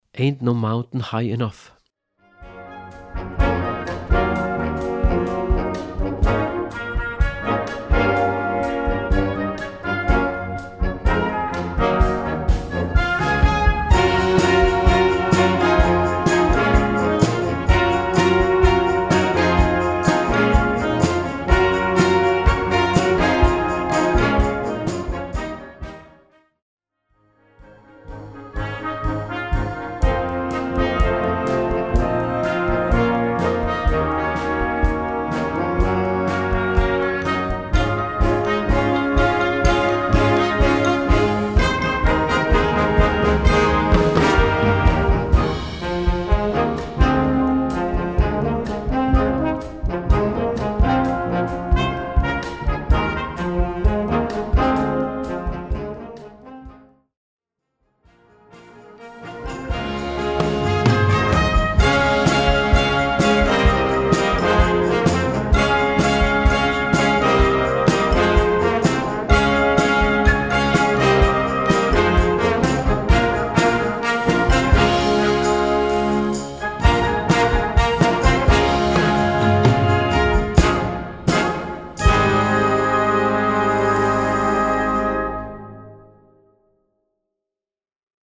Gattung: Modernes Jugendwerk
Besetzung: Blasorchester
This is to make the key easier to play.